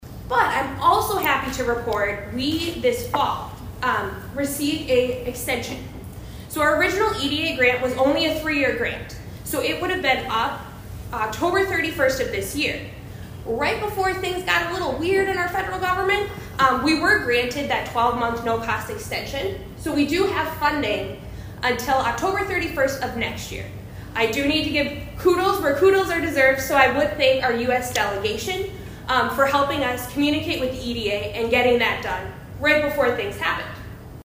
ABERDEEN, S.D.(HubCityRadio)- The Aberdeen Chamber of Commerce’s Chamber Connections Series continued Thursday at the K.O.Lee Public Library.